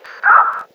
VOICE STOP.wav